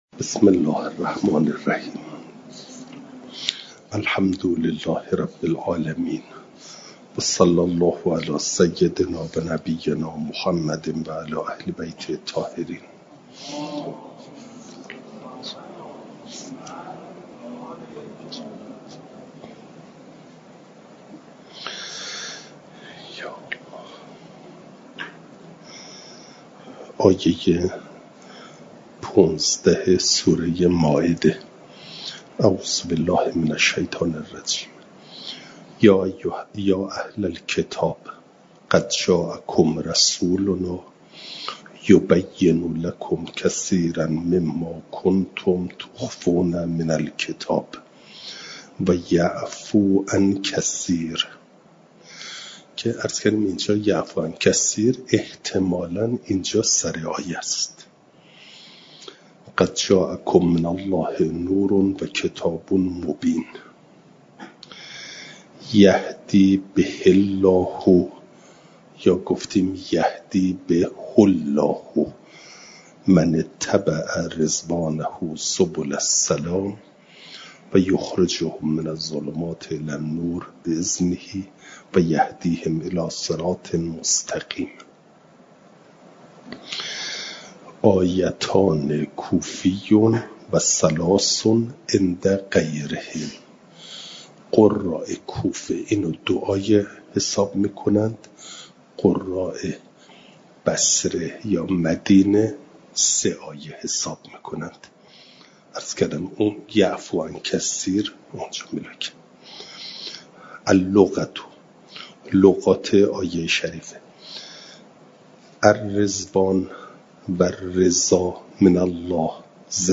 جلسه چهارصد و بیست و چهار درس تفسیر مجمع البیان